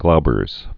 (gloubərz)